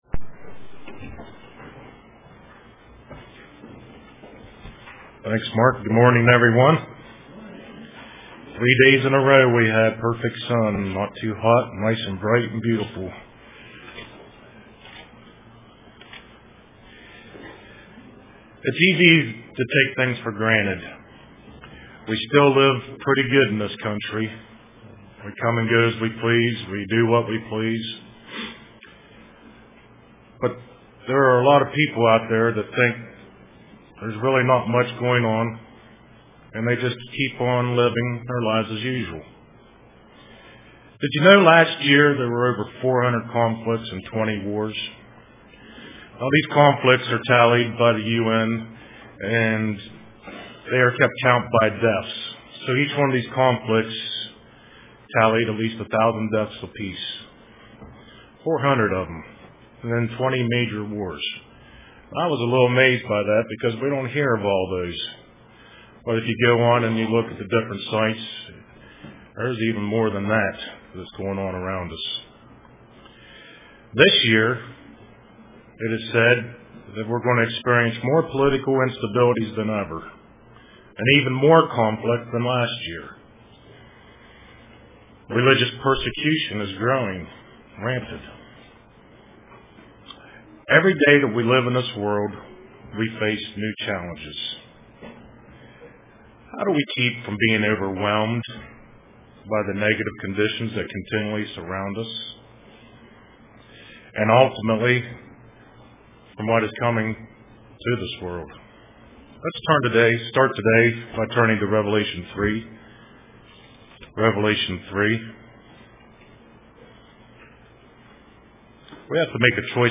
Print Perseverance UCG Sermon Studying the bible?